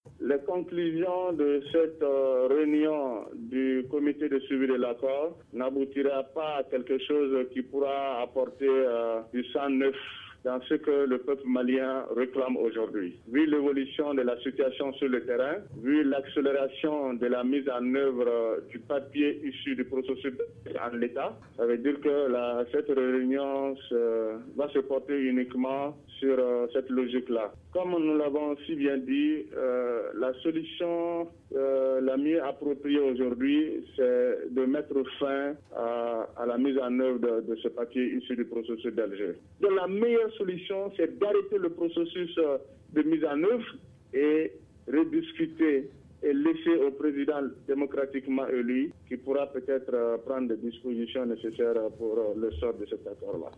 Dans une interview accordée à Studio Tamani, le ministre de la réconciliation nationale s’est félicité du drapeau malien qui flotte de nouveau , selon lui, à Kidal.